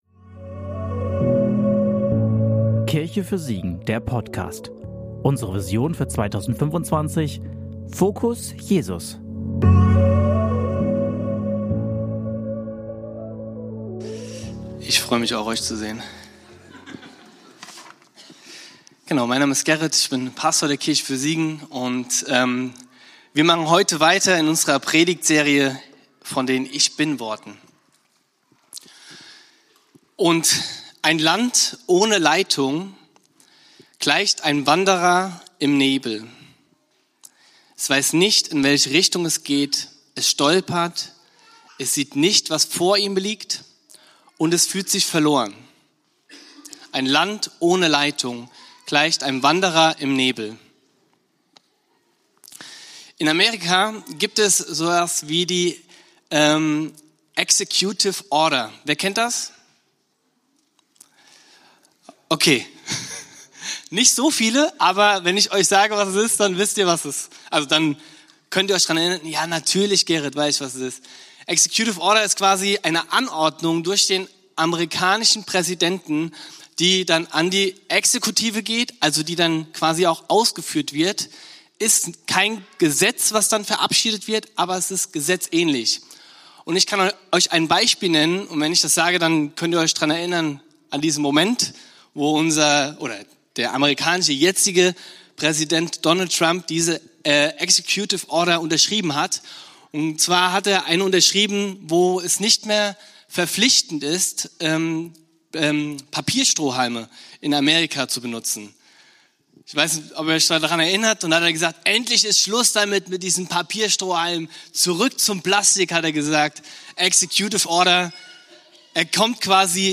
Predigt vom 07.09.2025 in der Kirche für Siegen